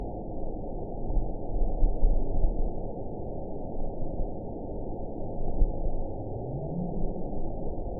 event 912106 date 03/18/22 time 14:18:39 GMT (3 years, 1 month ago) score 9.16 location TSS-AB09 detected by nrw target species NRW annotations +NRW Spectrogram: Frequency (kHz) vs. Time (s) audio not available .wav